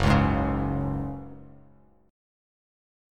F#sus2 chord